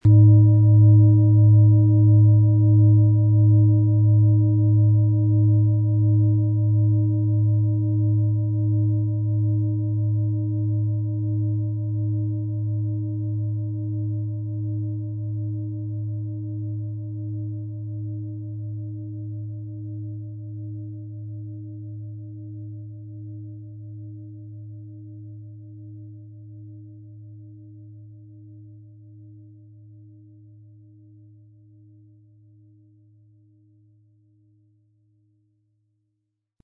Planetenton
Handgearbeitete tibetische Schale mit dem Planetenton Eros.
Um den Originalton der Schale anzuhören, gehen Sie bitte zu unserer Klangaufnahme unter dem Produktbild.
MaterialBronze